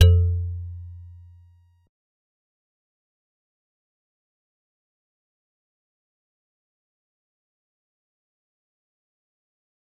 G_Musicbox-F2-pp.wav